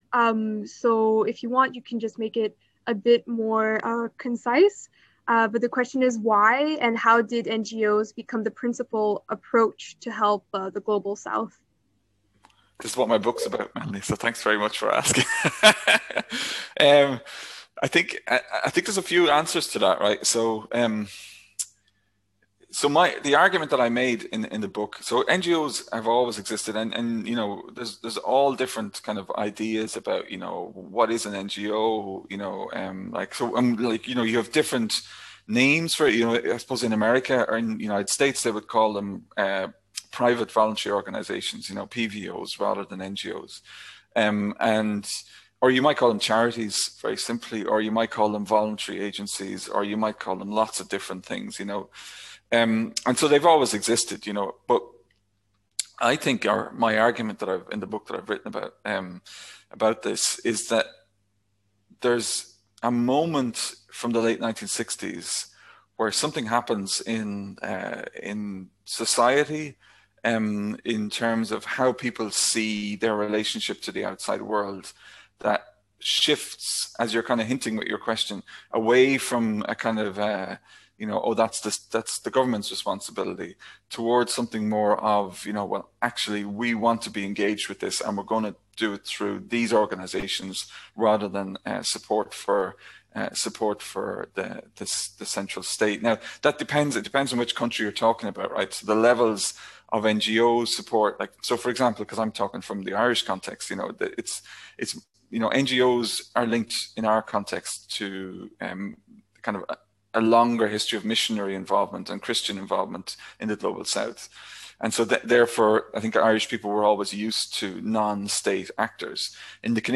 This part of the interview is split in two because of technical difficulties